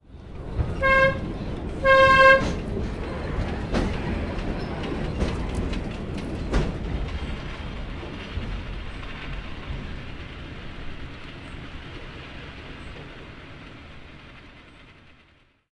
提比达博缆车 下降 从Carretera de les Aigües公路上的桥开始
声道立体声